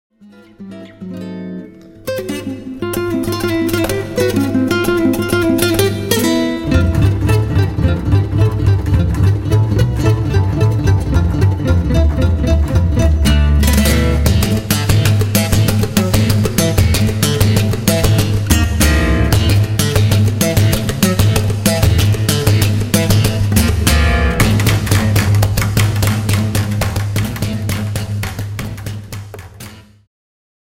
ジャンル Jazz-Rock系
Progressive
アコースティック
アバンギャルド
インストゥルメンタル